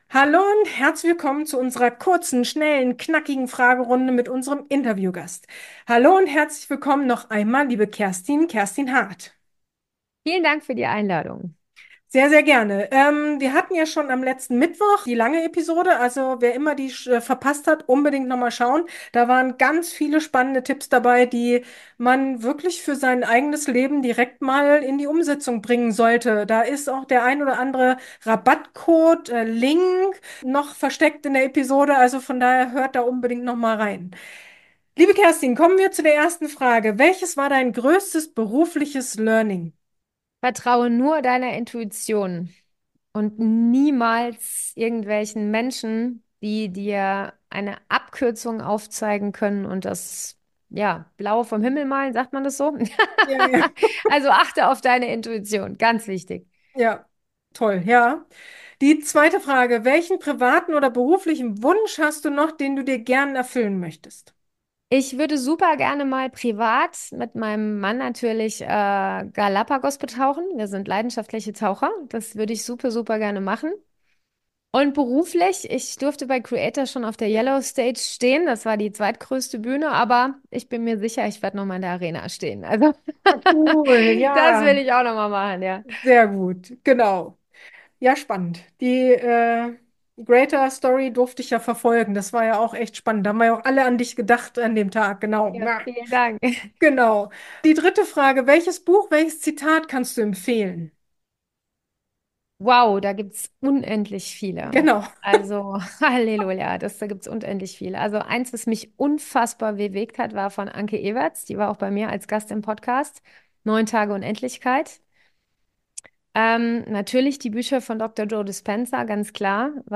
In dieser Freitags-Episode erwartet Dich eine schnelle Fragerunde